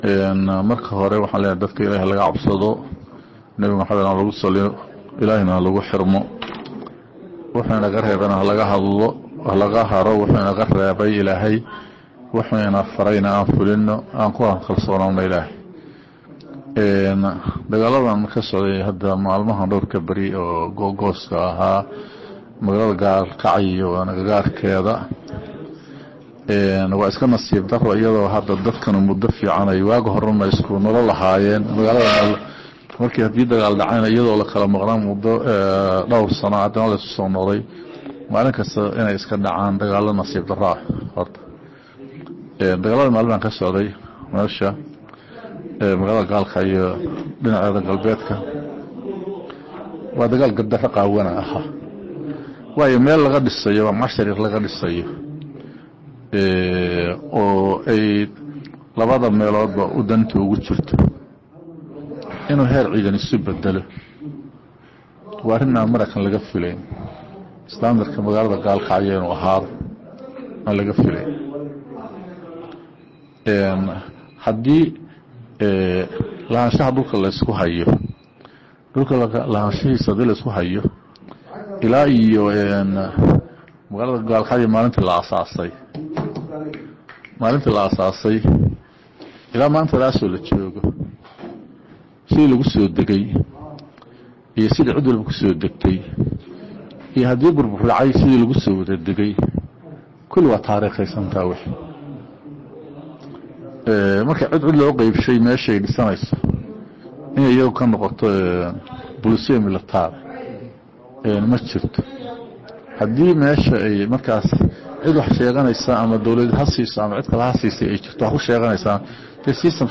15 okt 2016 (Puntlandes) Taliyaha ciidanka difaaca dowladda Puntland Gen. Siciid Maxamed Xirsi (Siciid dheere), oo waraysi siiyey radio Daljir isagoo kusugan magaalada Galkacyo ayaa shaaciyey in dowladda Puntland aysan cidna weerar ku ahayn laakiin ay is difaacayso.